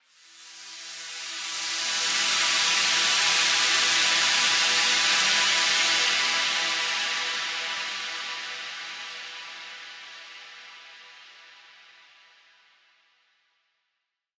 SaS_HiFilterPad03-E.wav